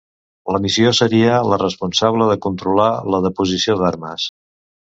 Pronunciado como (IPA)
[də.pu.zi.siˈo]